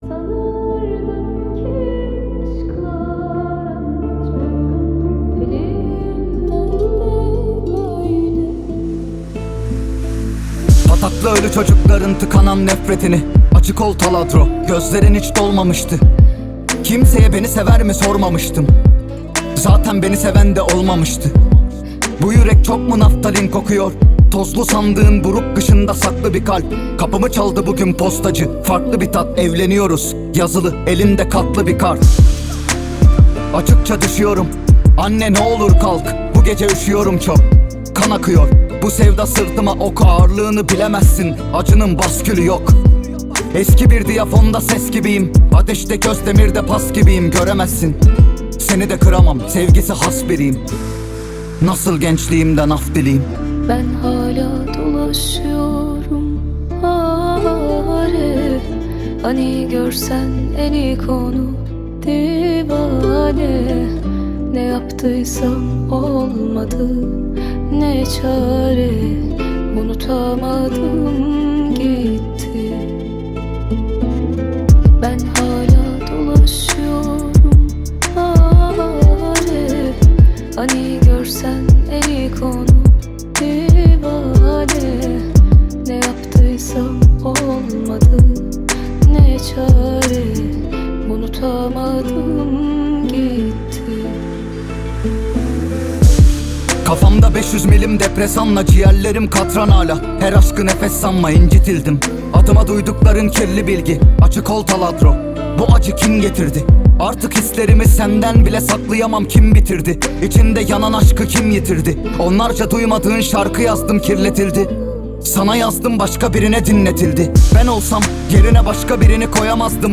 En Güzel Pop Müzikler Türkçe + Yeni şarkılar indir